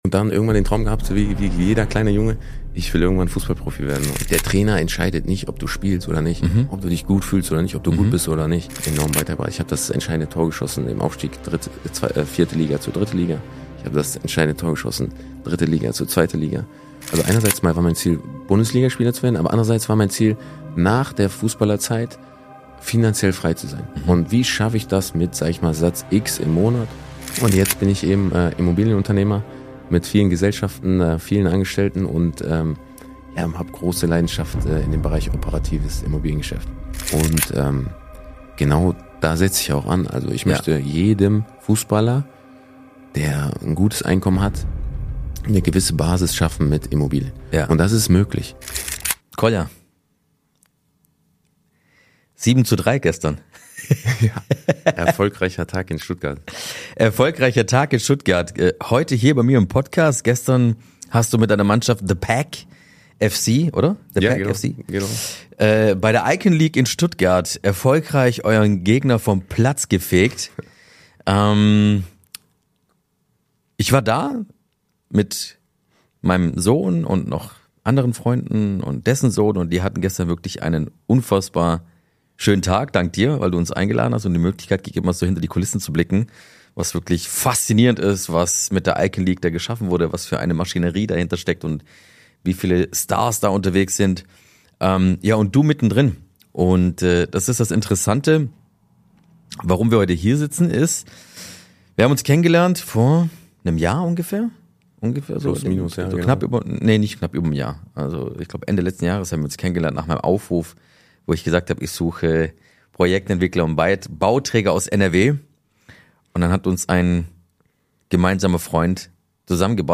Ein Gespräch über Geld, Karriereende und das, was nach dem Applaus wirklich zählt.